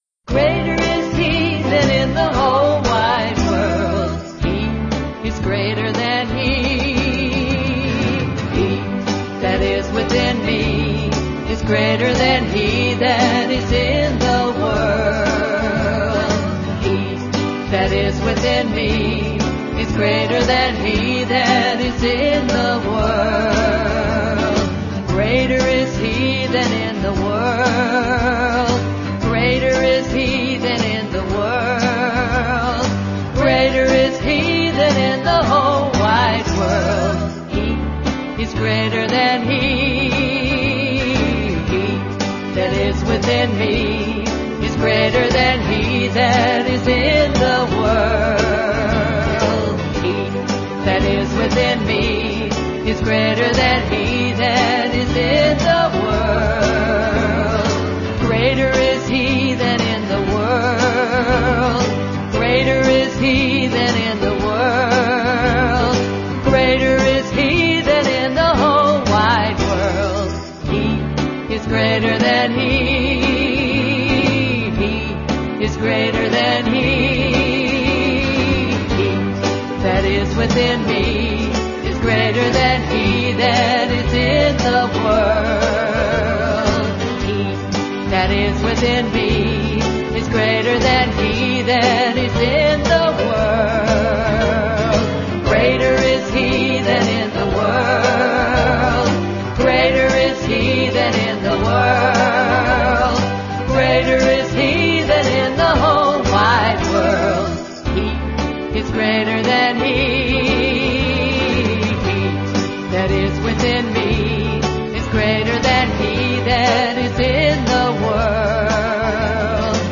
1. Devotional Songs
Major (Shankarabharanam / Bilawal) 8 Beat
8 Beat / Keherwa / Adi
Medium Fast